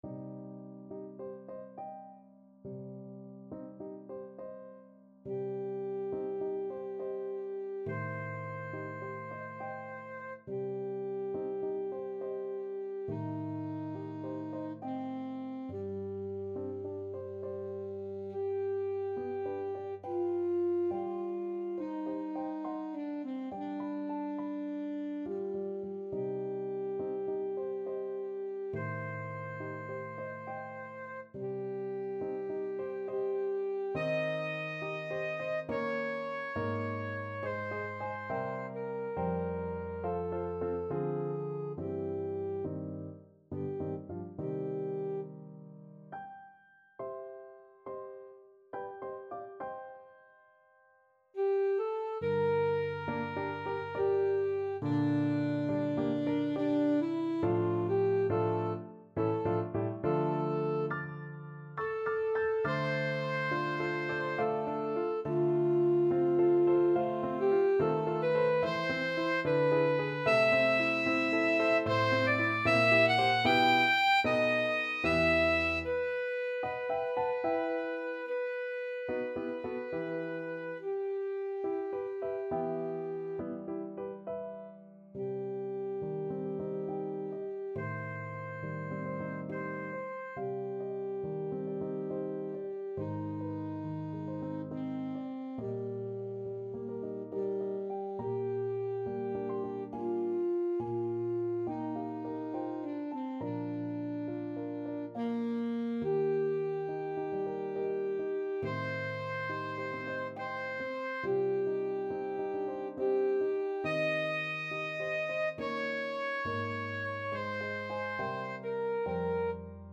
Alto Saxophone
C minor (Sounding Pitch) A minor (Alto Saxophone in Eb) (View more C minor Music for Saxophone )
3/8 (View more 3/8 Music)
G4-G6
Andante =69
Classical (View more Classical Saxophone Music)
strauss_horn_con1_op11_andante_ASAX.mp3